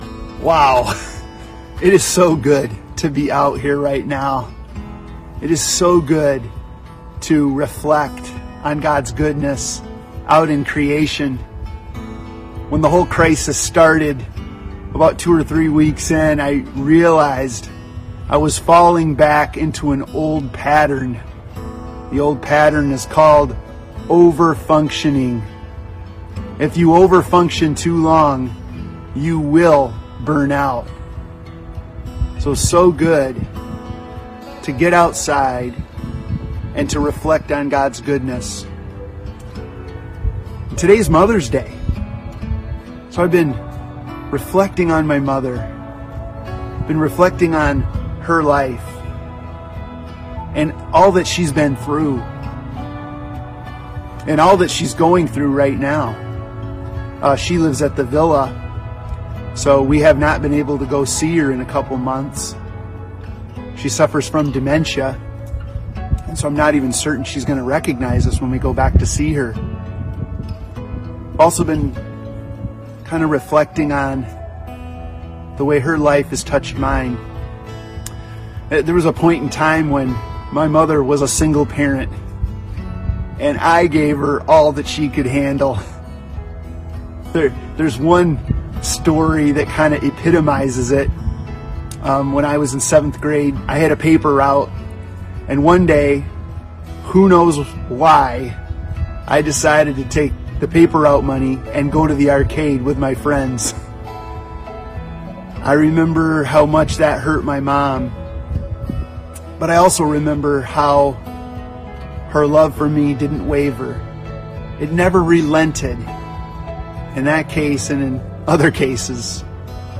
Mother's Day Service Type: Sunday Morning Preacher